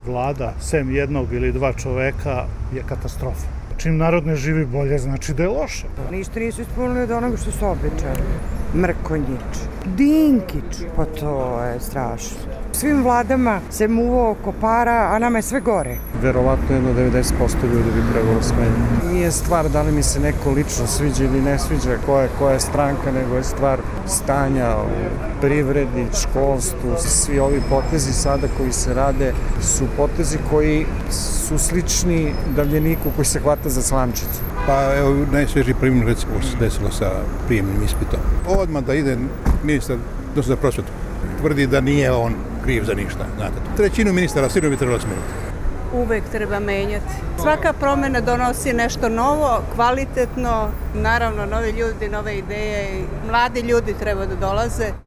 Beograđani za RSE kažu da sa nestrpljenjem očekuju promene u vladi.
Beograđani o rekonstrukciji vlade